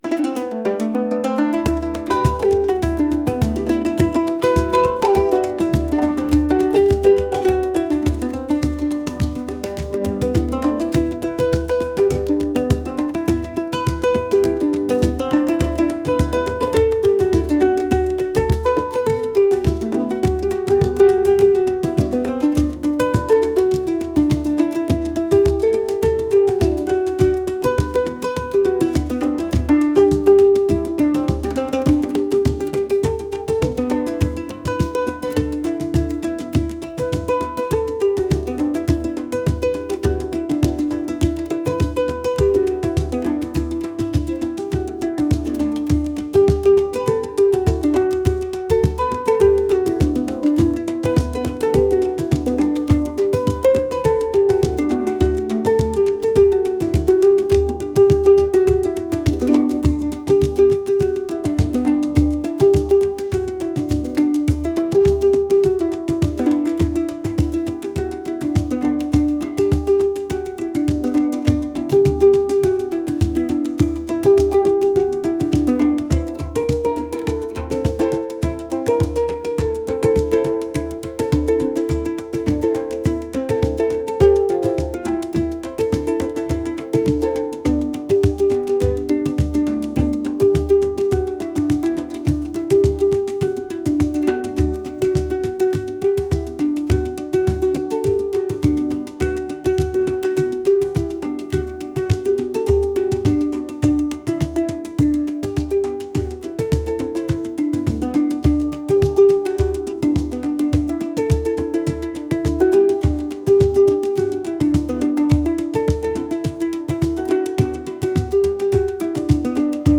rhythmic | folk | world